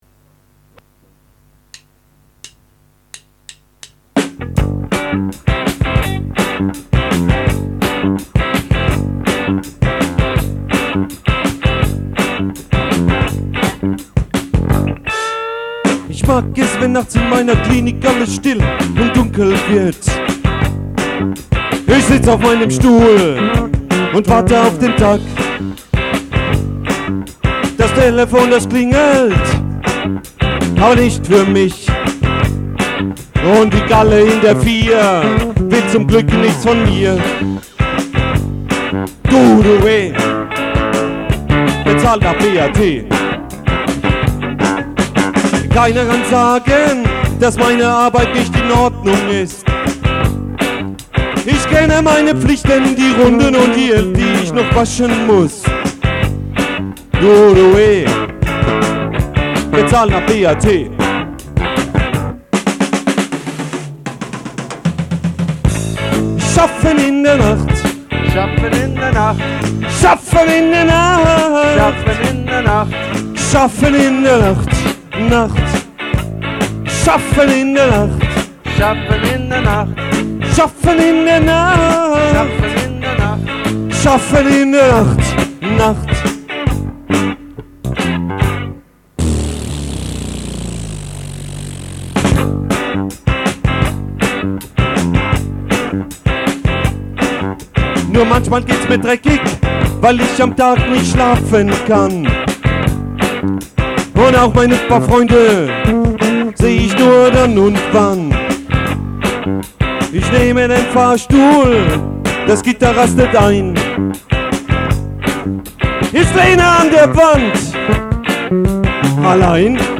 Gittare
Bass, Vocals
Schlagzeug
Saxophon, Vocals
Trompete
Possaune
Background Vocals: Verschiedene